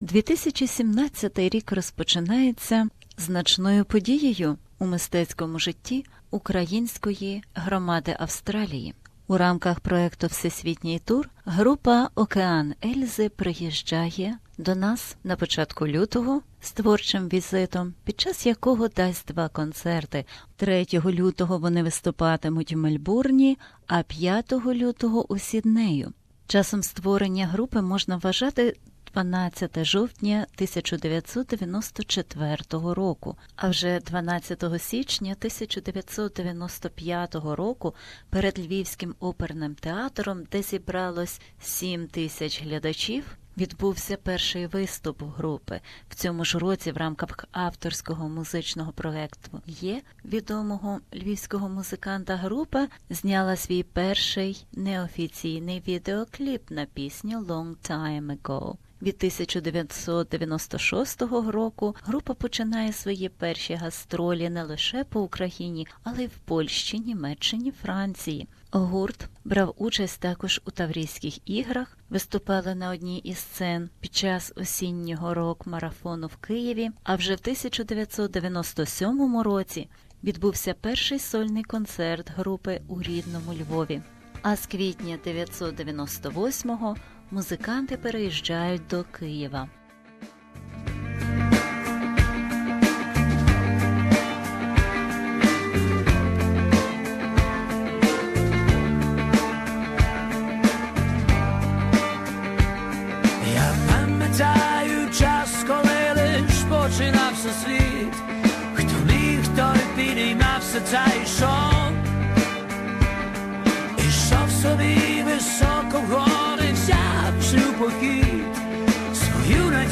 Interview with Svyatoslav Vakarchuk (the lead vocalist of Okean Elzy)